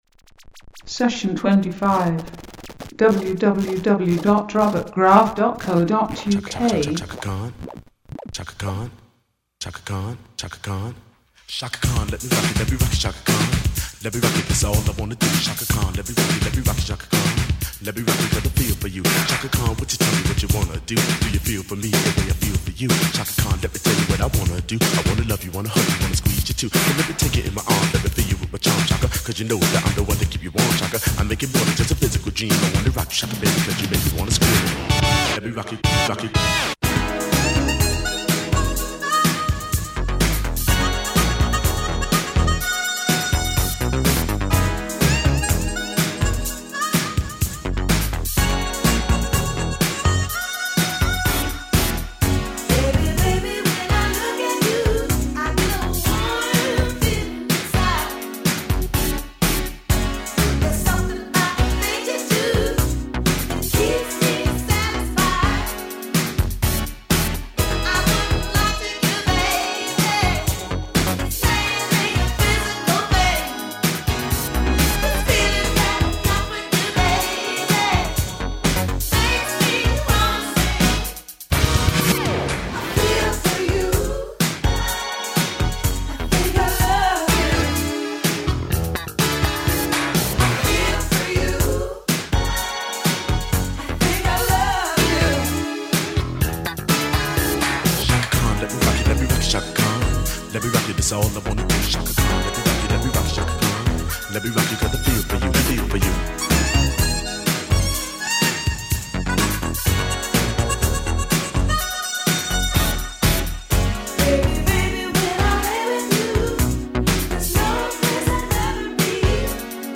This month’s podcast kicks off with a pop-theme